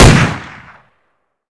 WR_fire.wav